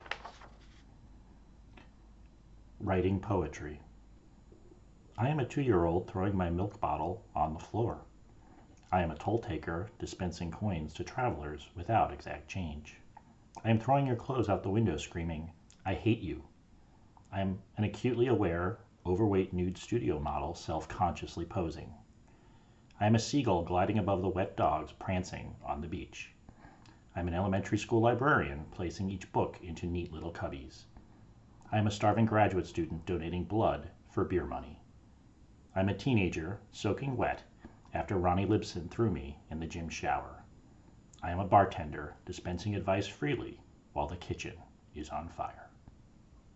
Hear me read the poem!